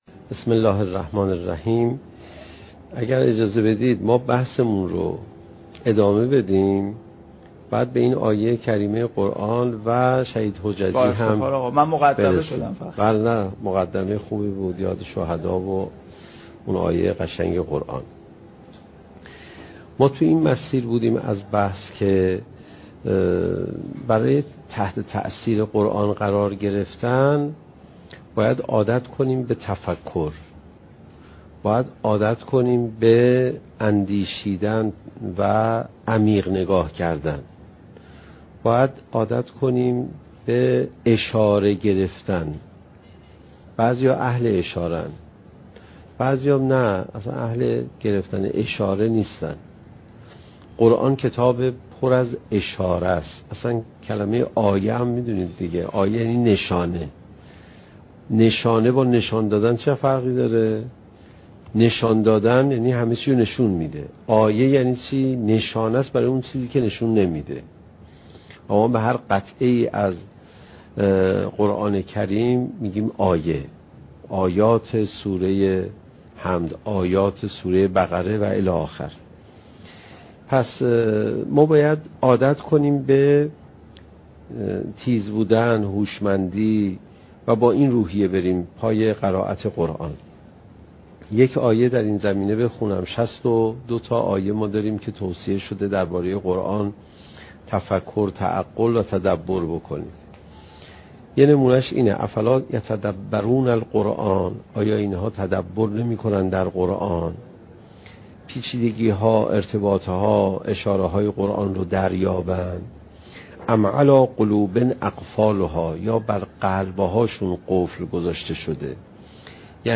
سخنرانی حجت الاسلام علیرضا پناهیان با موضوع "چگونه بهتر قرآن بخوانیم؟"؛ جلسه چهاردهم: "تاثیر قرآن کریم با تفکر"